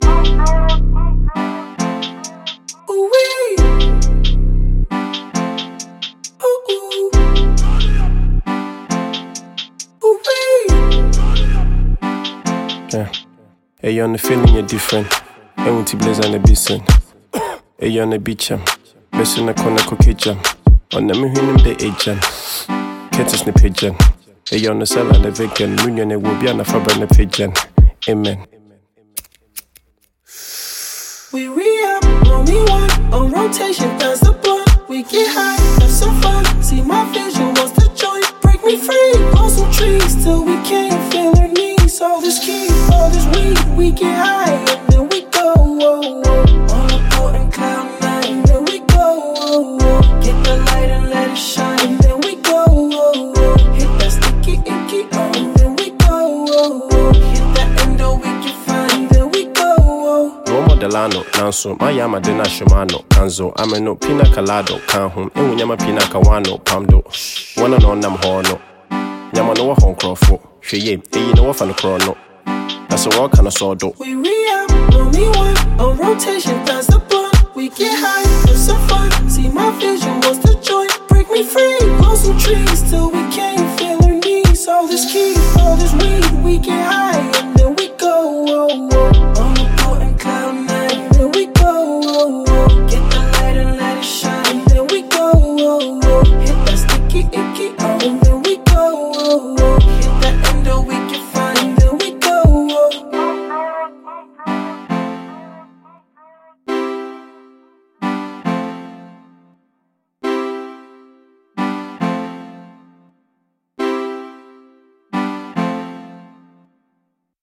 lovely banger